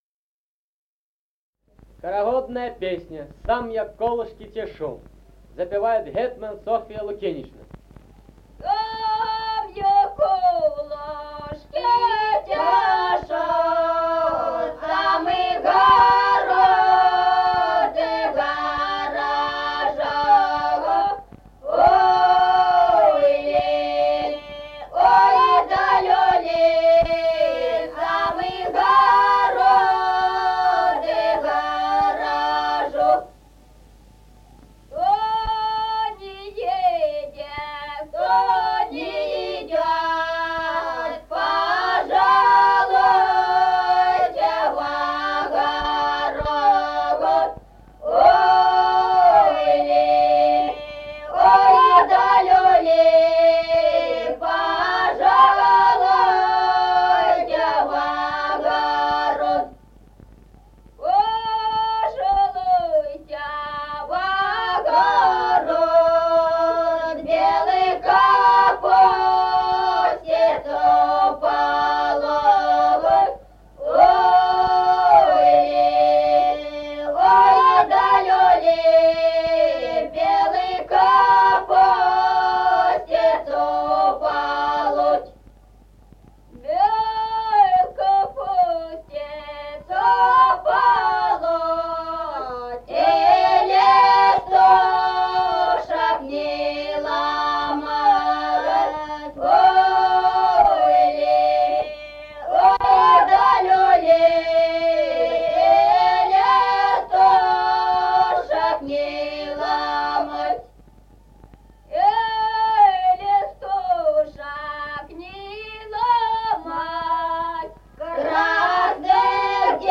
Народные песни Стародубского района «Сам я колышки тешу», карагодная.
(подголосник)
(запев).